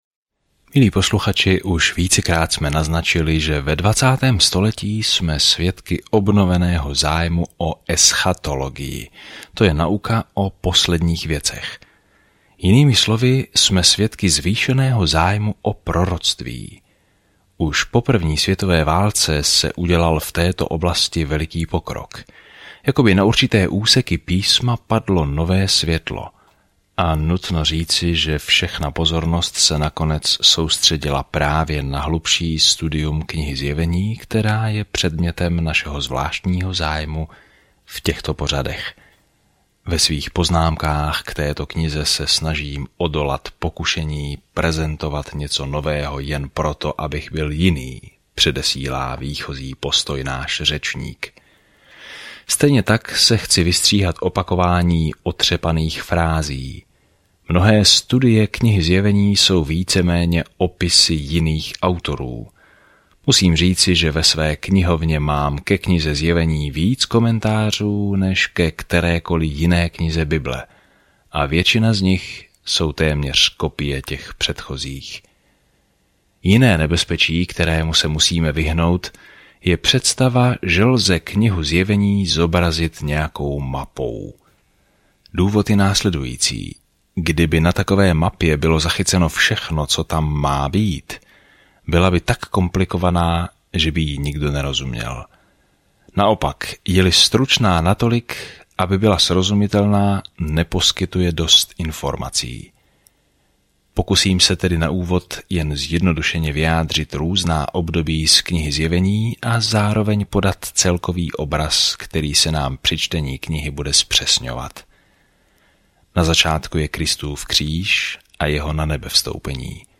Písmo Zjevení 1:1 Den 2 Začít tento plán Den 4 O tomto plánu Zjevení zaznamenává konec rozsáhlé časové osy dějin s obrazem toho, jak bude se zlem konečně zacházeno a Pán Ježíš Kristus bude vládnout ve vší autoritě, moci, kráse a slávě. Denně procházejte Zjevení a poslouchejte audiostudii a čtěte vybrané verše z Božího slova.